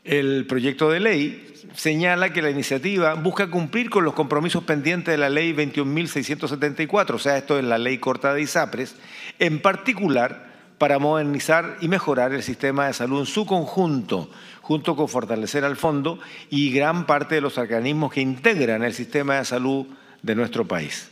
El presidente de la Comisión de Salud, senador Iván Flores, explicó que la iniciativa responde a los compromisos derivados de la ley corta de Isapres y busca dar un impulso al sistema público.